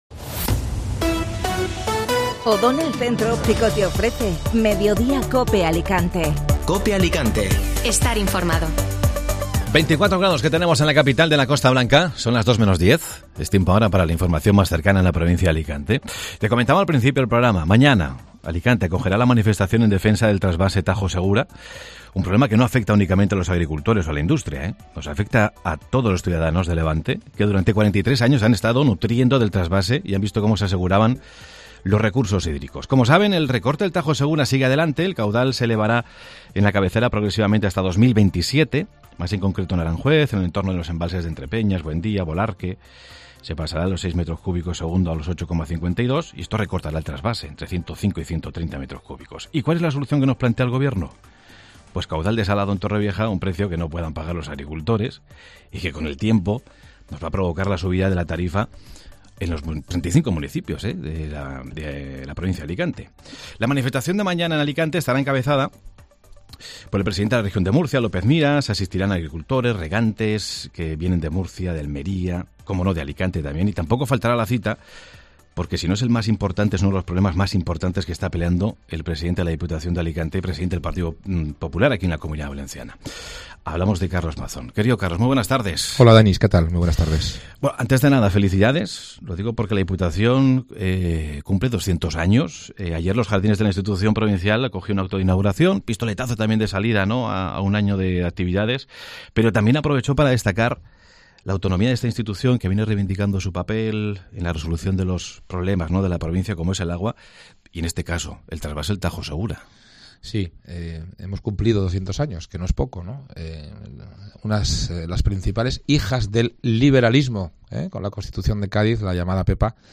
AUDIO: Escucha la entrevista al presidente de la Diputación de Alicante en Mediodía COPE